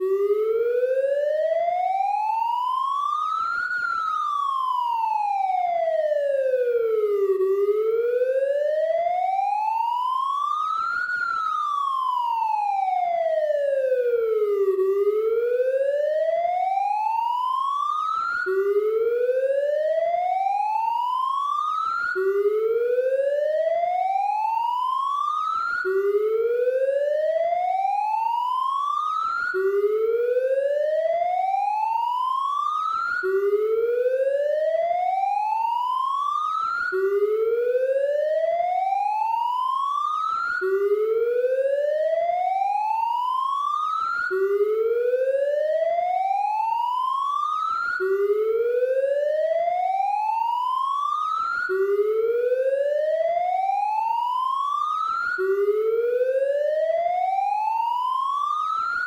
警用警报器(远)
描述：这个声音是用蜂鸣器发出的，它是一个警察警笛，听起来像是在附近。